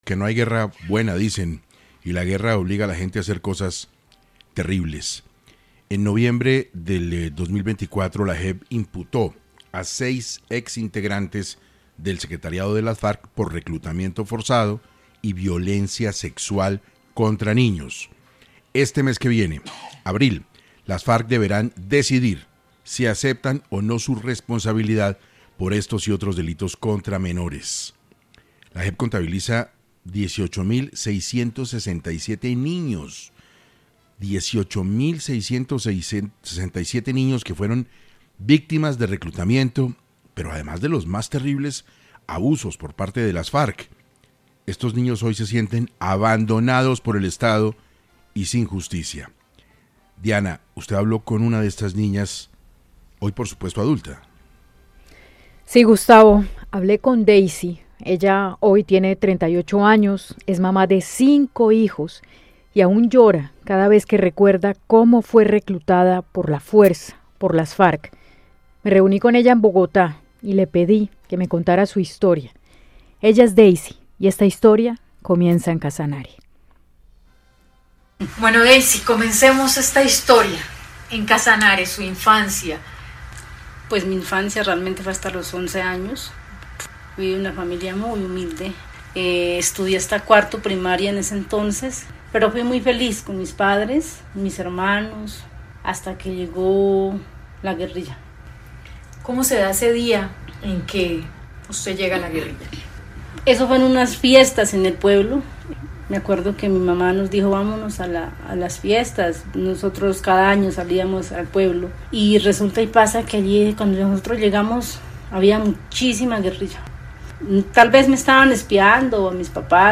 6AM logró el cruel relato de una excombatiente, que fue reclutada a la fuerza y que deberá comparecer por varios crímenes por los que fue obligada a realizar.